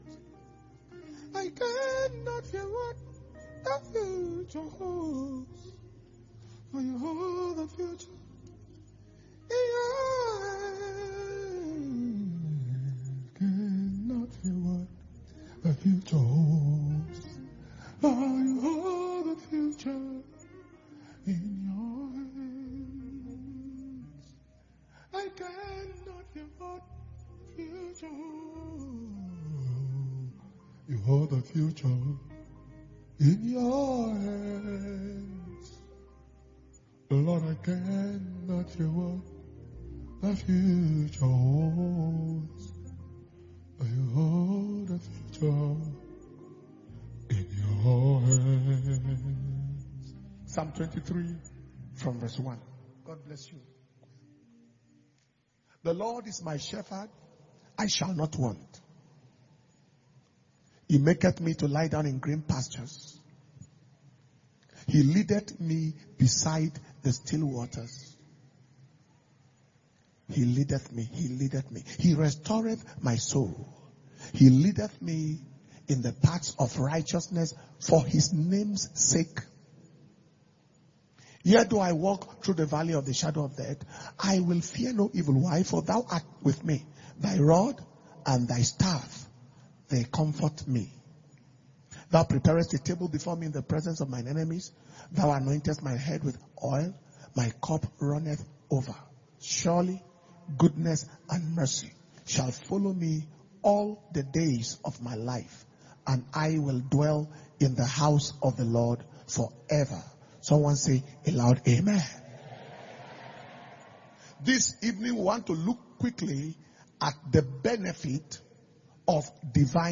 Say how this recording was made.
Power Communion Service/Overflowing Grace And Glory Fast – Day 3 – Wednesday 12th January 2022